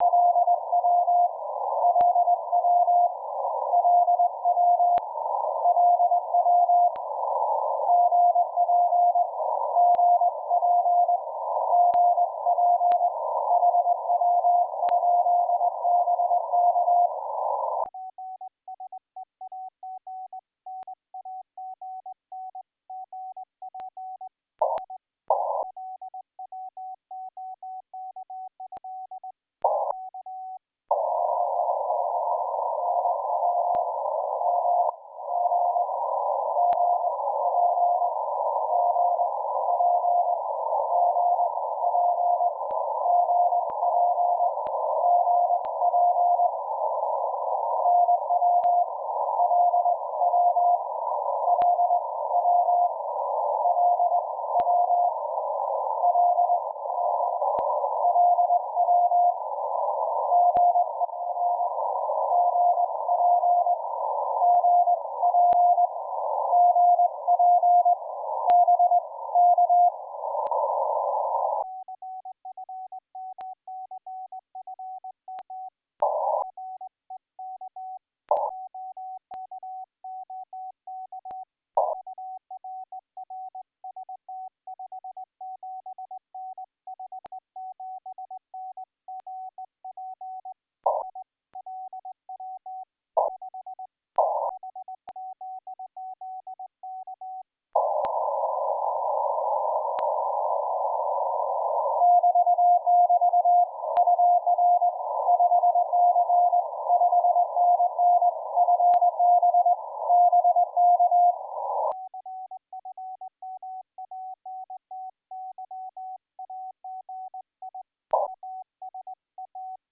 Сигнал немного гуляет, но я его слышу одинаково на обе антенны.
Ослабление сигнала с 00:56 по 01:05 - переключался с Яги на Виндом.